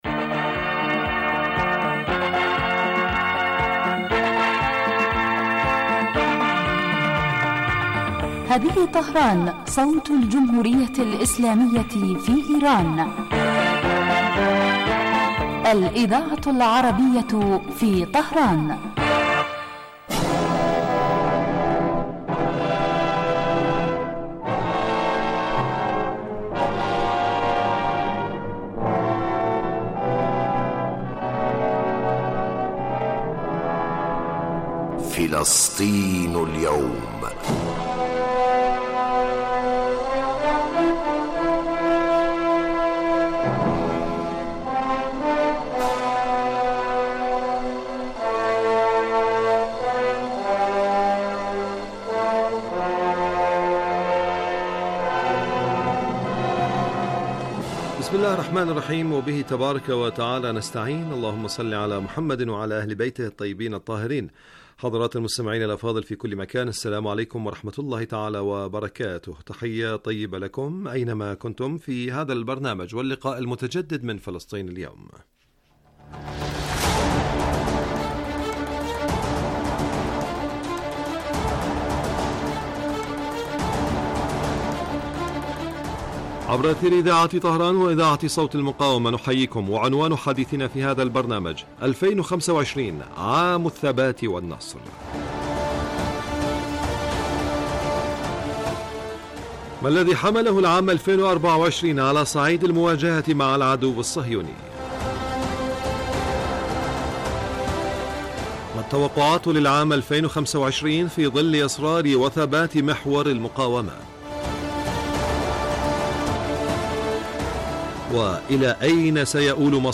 يبدأ البرنامج بمقدمة يتناول فيها المقدم الموضوع ثم يطرحه للنقاش من خلال تساؤلات يوجهها للخبير السياسي الضيف في الاستوديو . ثم يتم تلقي مداخلات من المستمعين هاتفيا حول الرؤى التي يطرحها ضيف الاستوديو وخبير آخر يتم استقباله عبر الهاتف ويتناول الموضوع بصورة تحليلية.